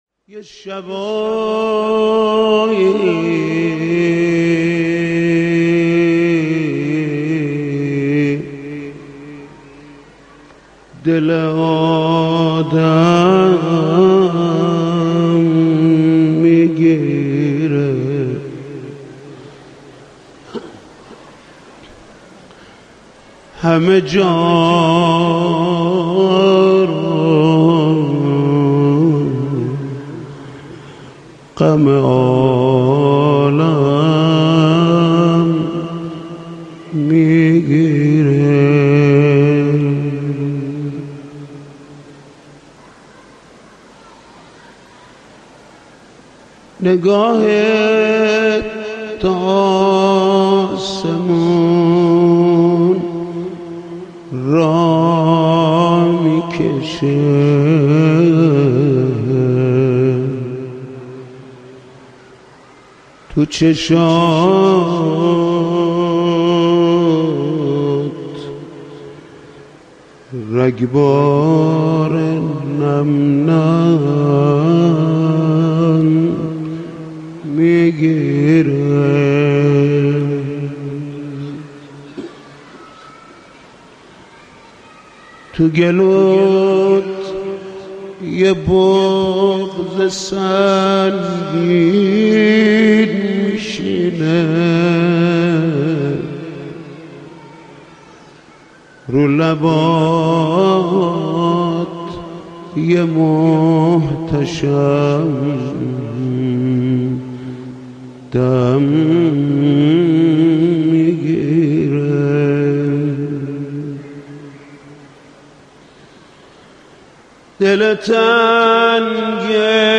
حاج محمود کریمی/گلچین مداحی های شب های ششم تا هشتم ماه مبارک رمضان۹۷/مشهد مقدس
مناجات